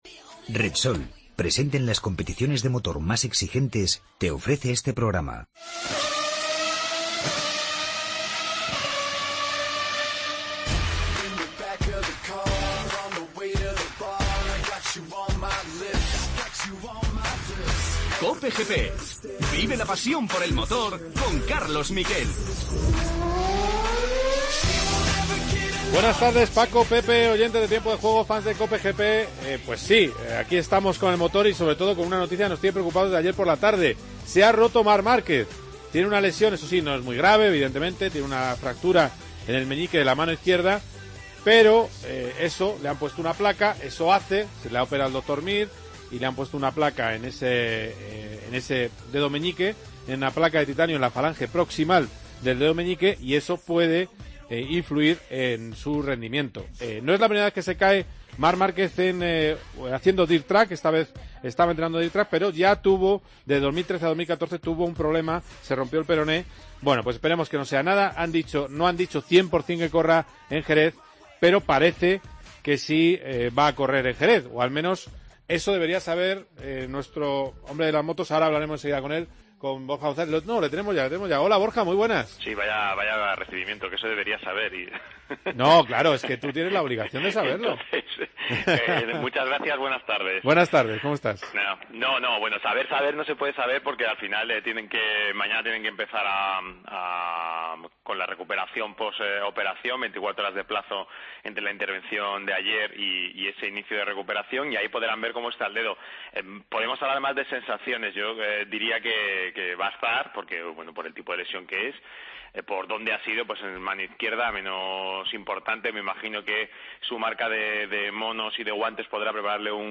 Titulares del día.